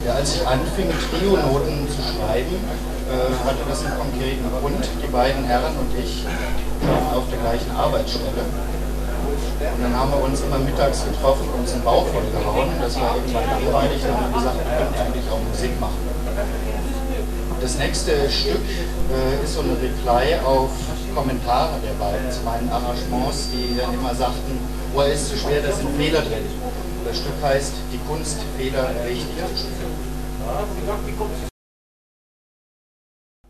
Ansage